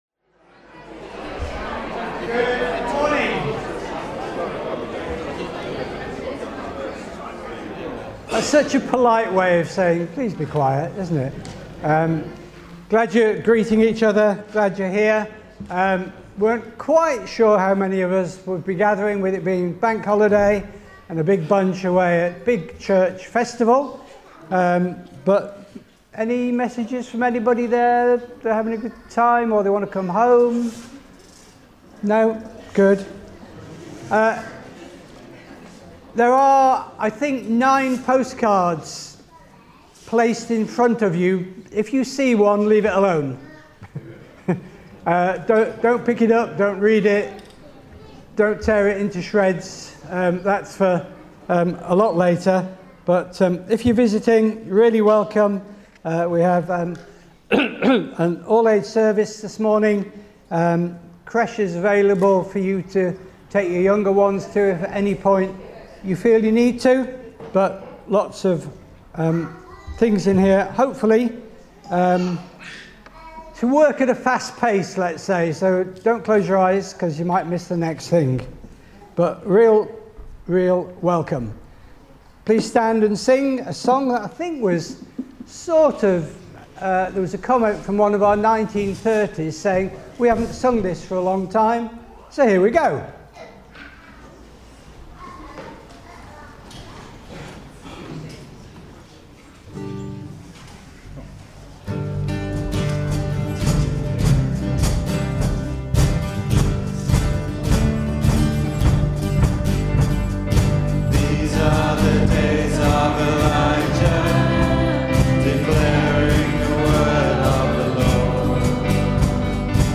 24 August 2025 – Morning Service
Service Type: Morning Service